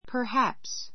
perhaps A2 pə r hǽps パ ハ プス 副詞 たぶん; もしかすると Perhaps I will come—but perhaps I won't. Perhaps I will come—but perhaps I won't. 来るかもしれないし, 来ないかもしれない.